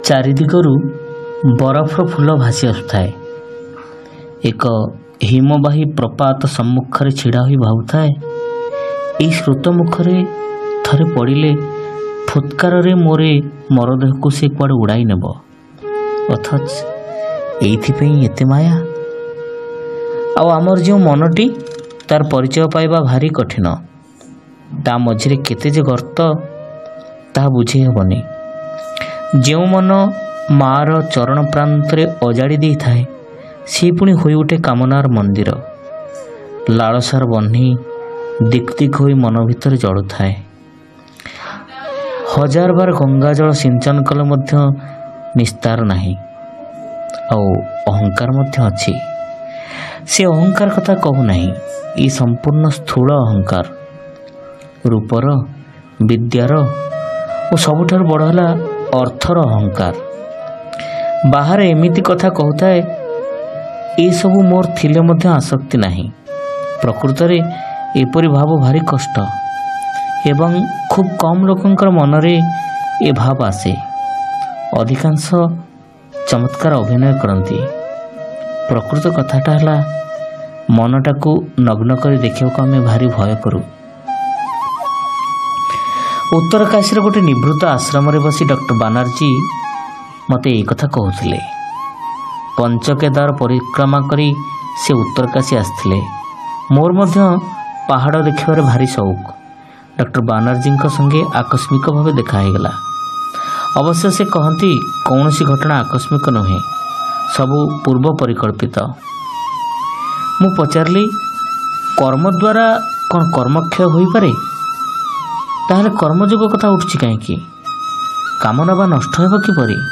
ଶ୍ରାବ୍ୟ ଗଳ୍ପ : ରହସ୍ୟମୟ ଅନୁଭବ - ଆଲୋକରେ ଅଭିଷେକ